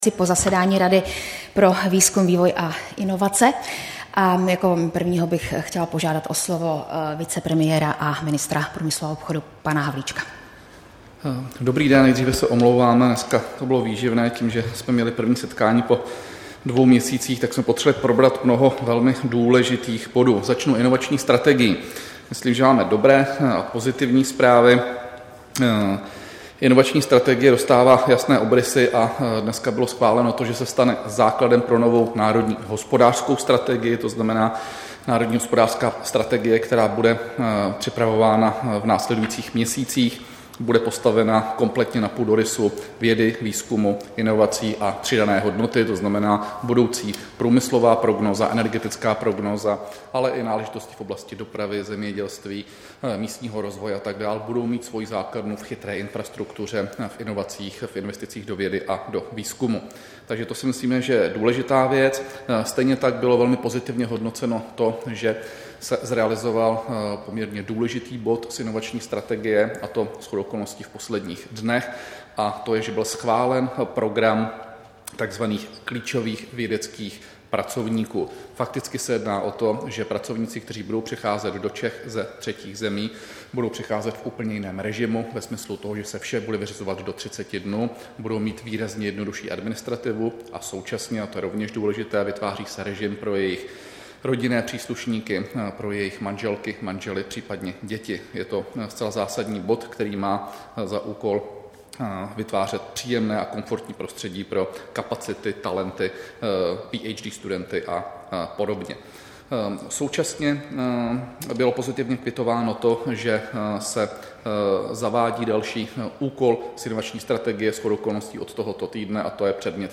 Tisková konference po jednání Rady pro výzkum, vývoj a inovace, 6. září 2019